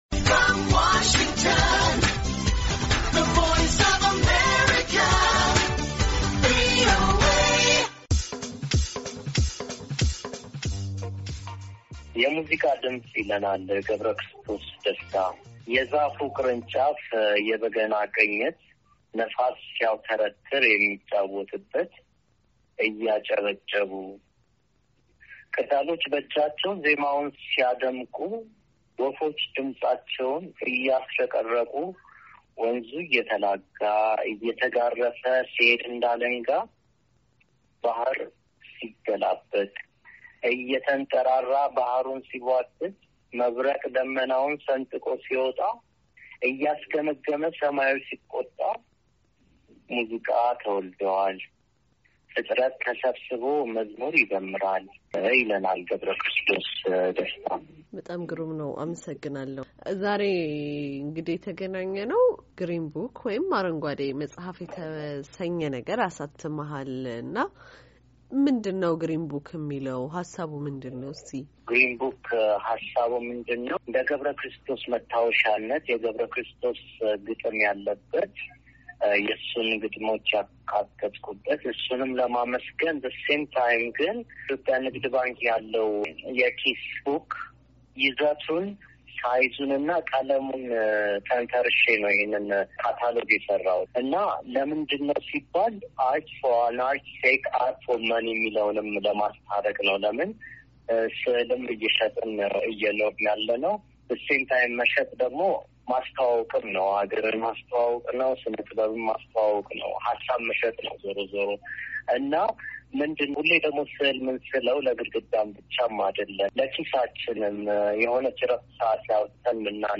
ከዚህ በተጨማሪም የሰዓሊ፣ ገጣሚ እና ባለቅኔ ገ/ክርስቶስ ደስታ የግጥም ስራዎች ያካተተ እና የእራሱን የተለያዩ የስነጥበብ ስራዎች ስብስብ የያዘ አርንጓዴ የኪስ መጽሃፍ አሳትሟል፡፡ ቃለምልልሱ